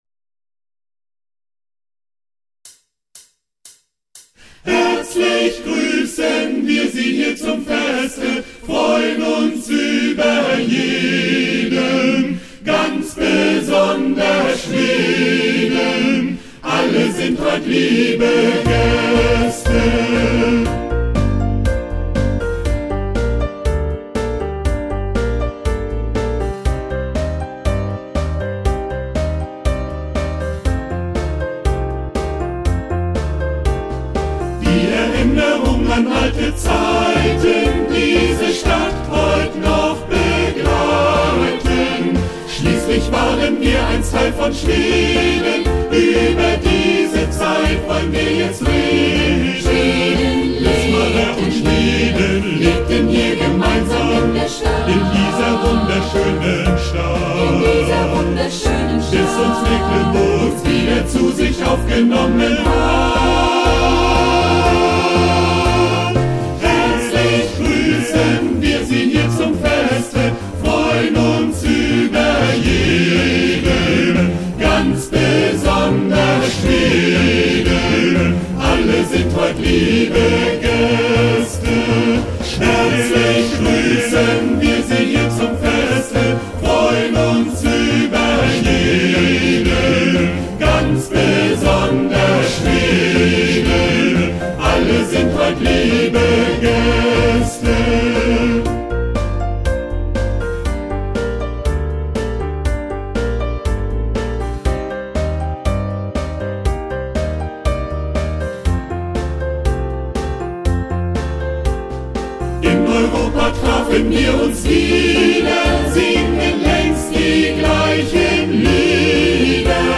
"Perlmutt" bei der Eröffnungsveranstaltung des Schwedenfest 2007 auf der großen NDR-Bühne auf dem Wismarer Marktplatz
Der Kammerchor Perlmutt bei seinem ersten öffentlichen Auftritt!